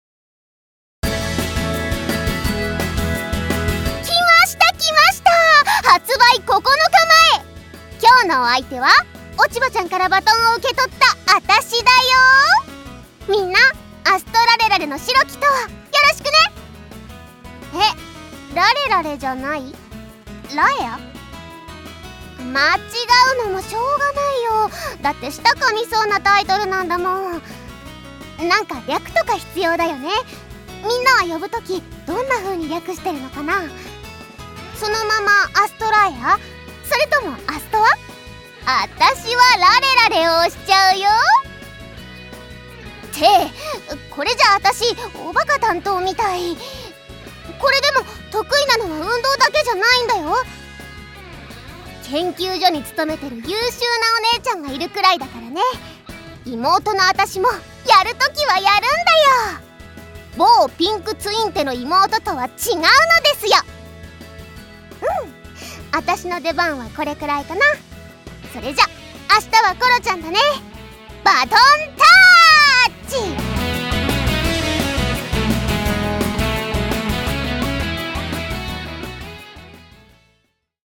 『アストラエアの白き永遠』 発売9日前カウントダウンボイス(一夏)を公開